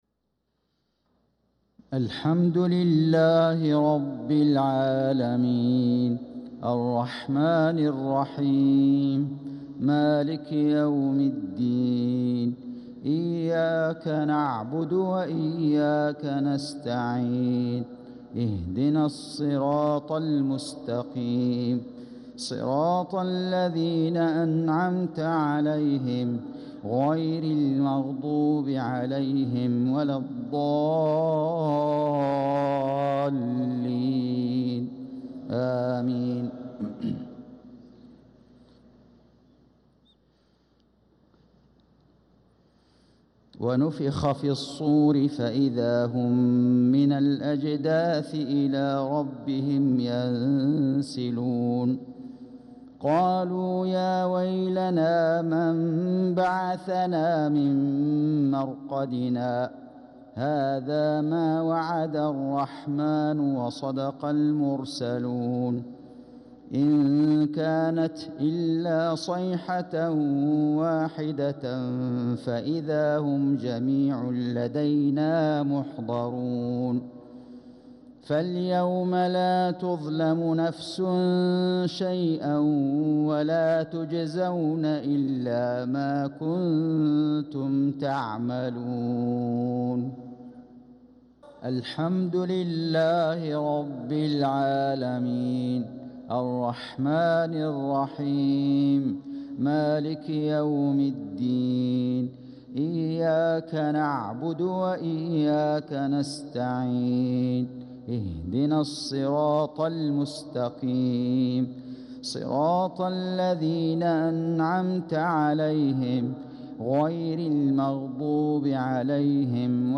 صلاة المغرب للقارئ فيصل غزاوي 18 ذو الحجة 1445 هـ